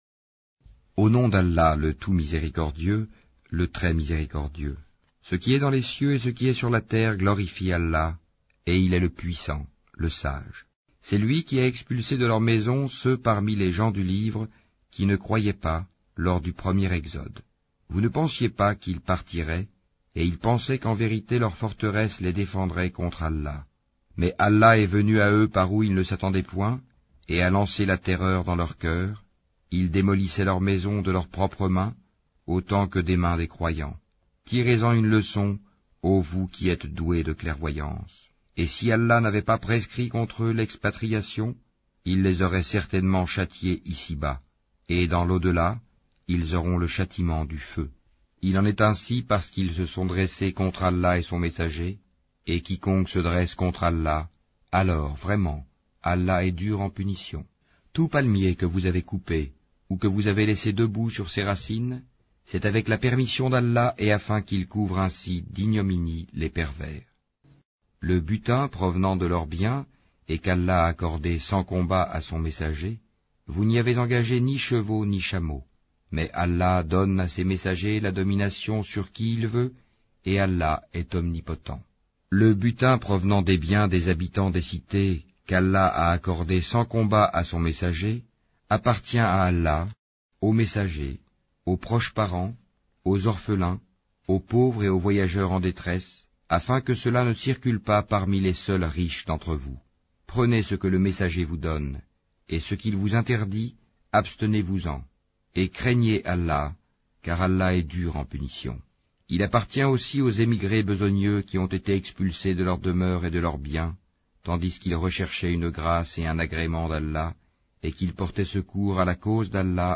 Al-Hachr Lecture audio